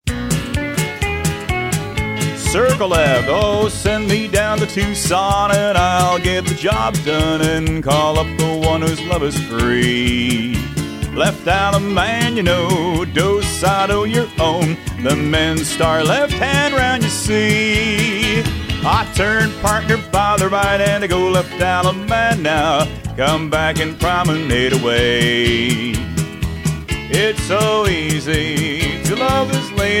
Instrumental
Vocal